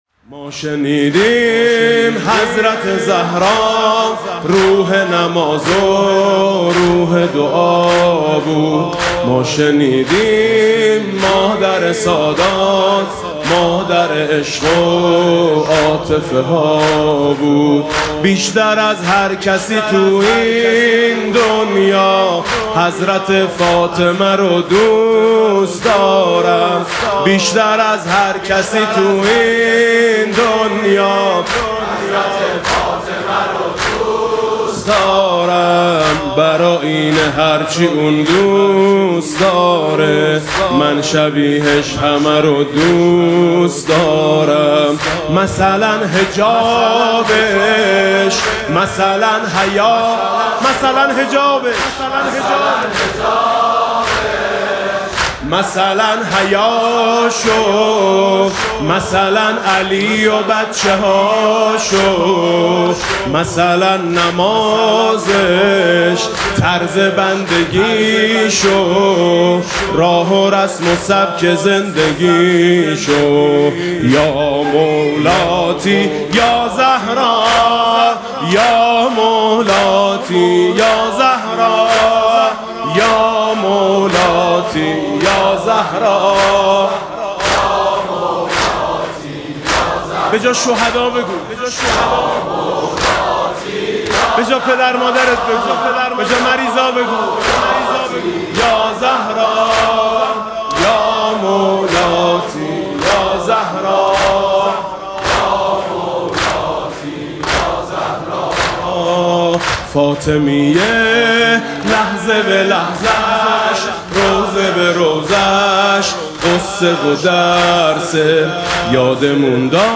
[آستان مقدس امامزاده قاضي الصابر (ع)]
عنوان: شب شهادت حضرت زهرا (س)